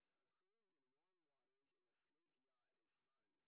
sp24_street_snr30.wav